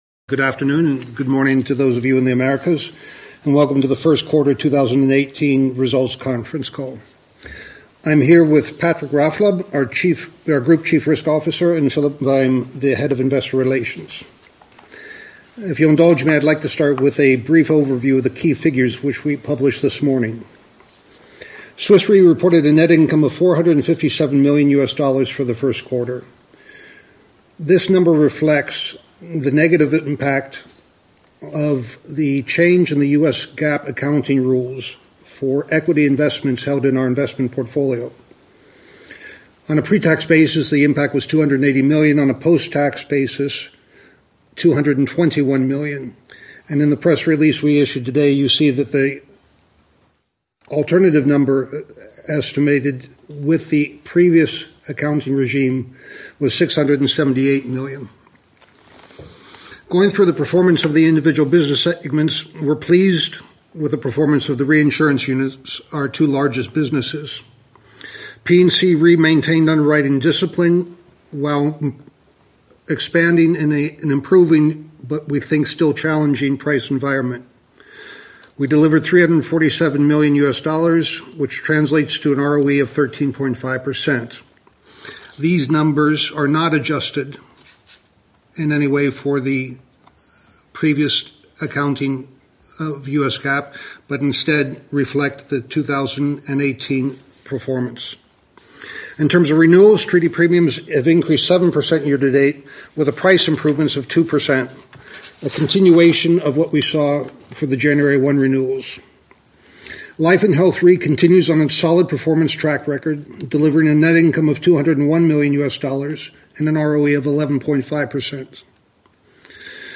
Analysts Conference call recording
2018_q1_qa_audio.mp3